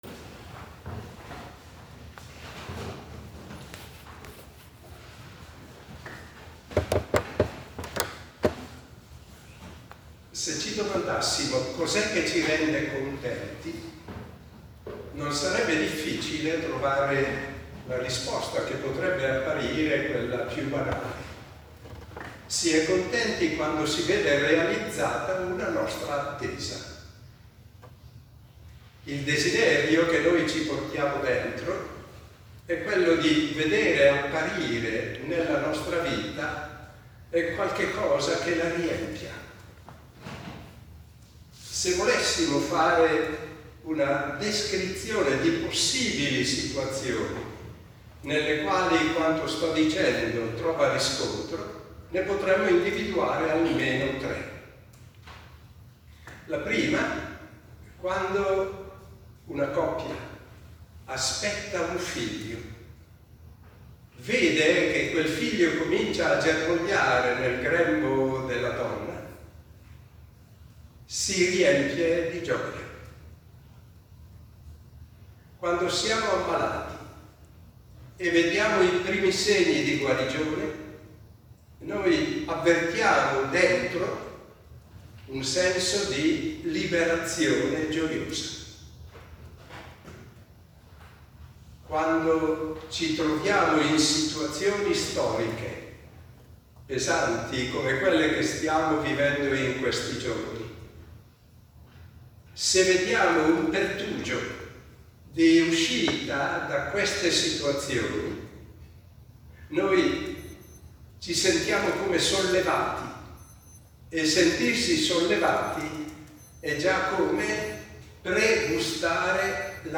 riflessione: Maria, causa della nostra gioia